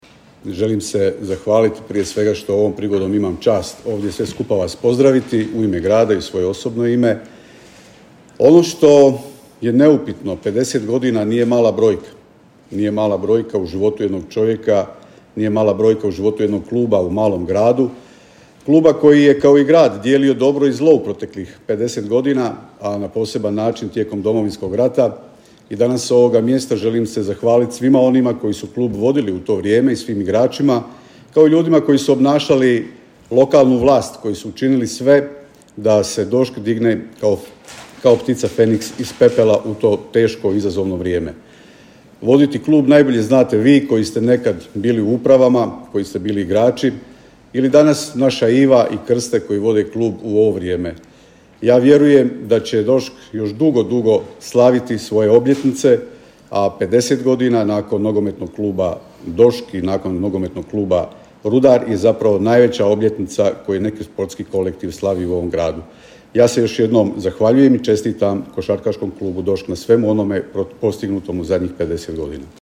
Okupljenim košarkašima na proslavi 50 godina DOŠK-a obratio se i gradonačelnik Josip Begonja: